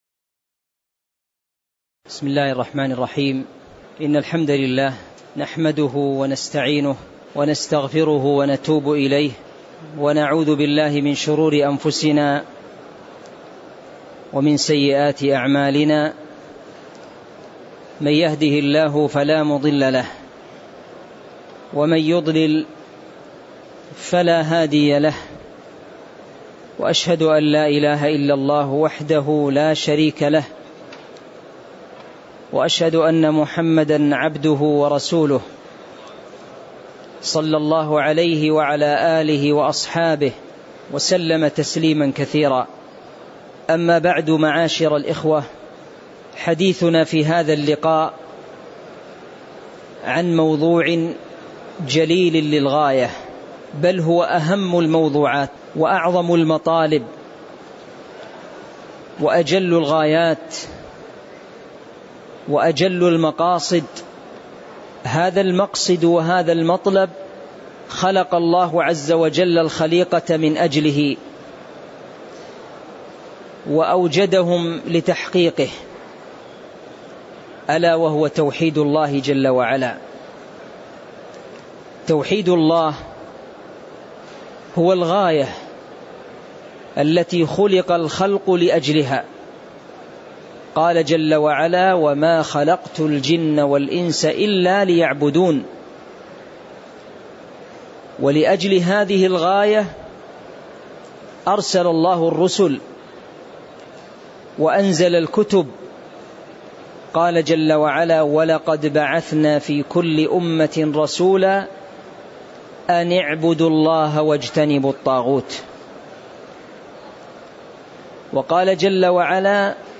تاريخ النشر ٢٦ ذو الحجة ١٤٤٣ هـ المكان: المسجد النبوي الشيخ